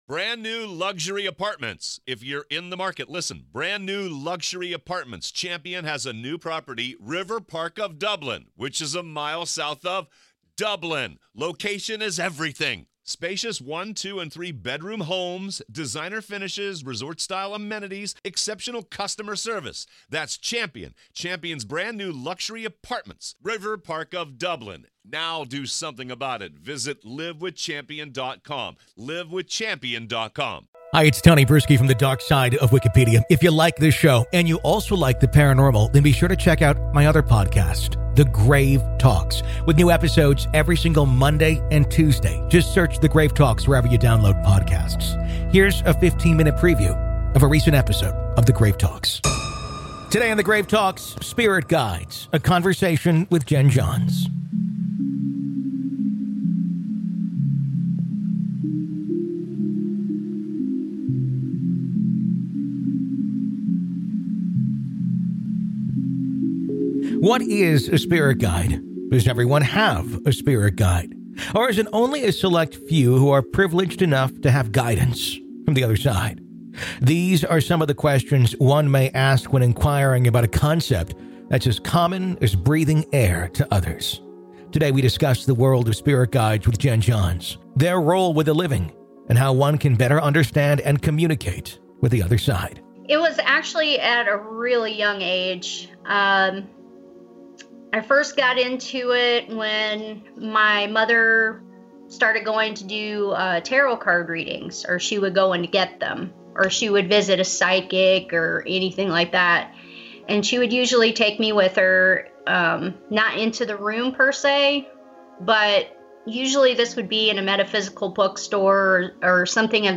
Daily True Crime News & Interviews